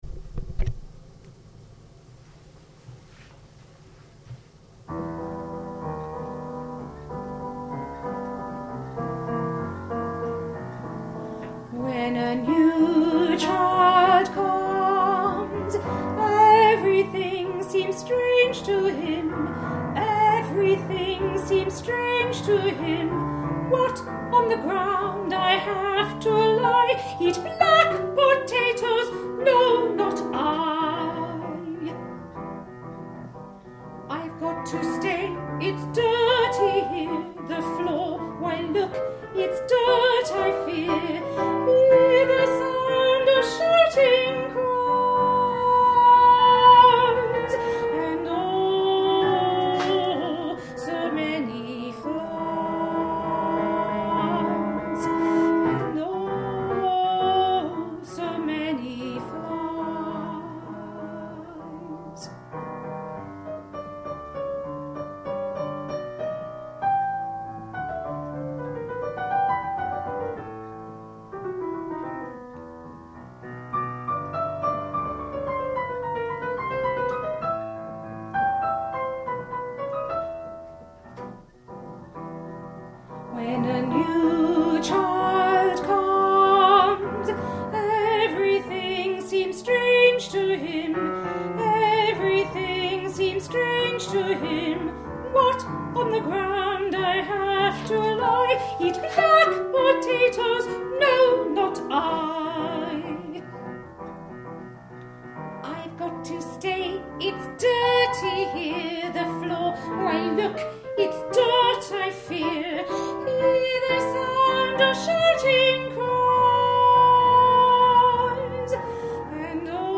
Vocal & Piano Audio of When A New Child Comes